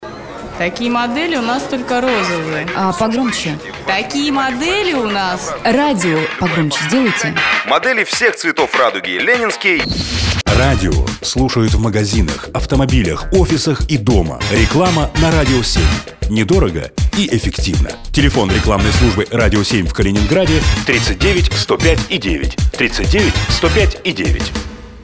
Игровой ролик (несколько актеров)